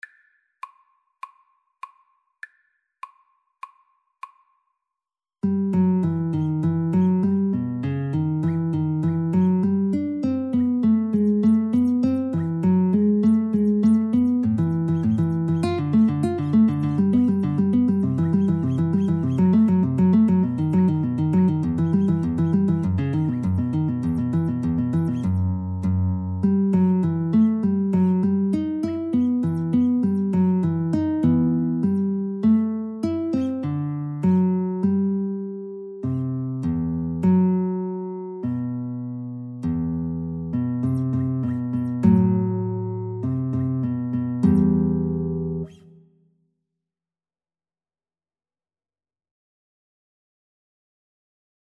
Classical (View more Classical Guitar Duet Music)